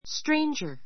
stranger A2 stréindʒə r スト レ インヂャ 名詞 ❶ 見知らぬ人, よその人, 他人 He is a stranger [no stranger] to me.